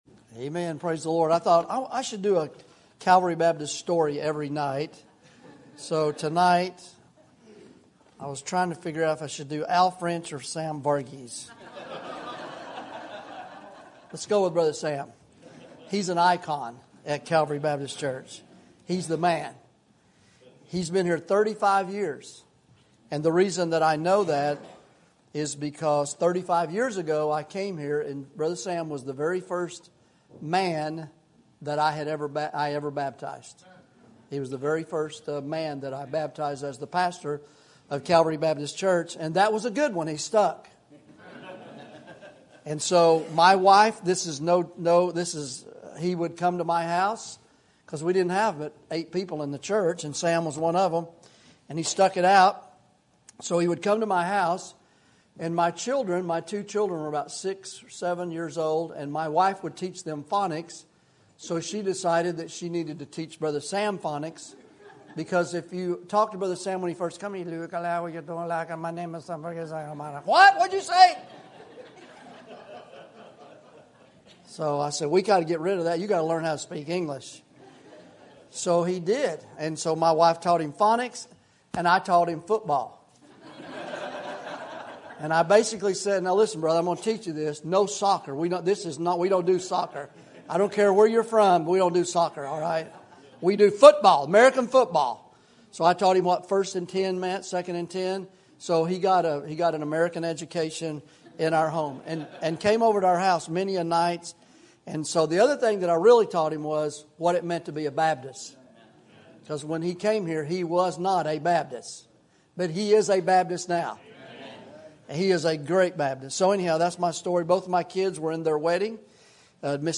Sermon Topic: Winter Revival Sermon Type: Special Sermon Audio: Sermon download: Download (20.57 MB) Sermon Tags: Matthew Revival Jesus Prayer